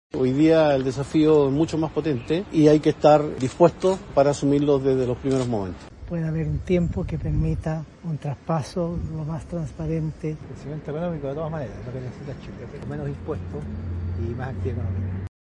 Se les preguntó por las expectativas y Alvarado habló de los desafíos por asumir desde el minuto uno, García Ruminot de trabajar por un correcto traspaso de mano y Quiroz afirmó que lo central es recuperar la actividad económica.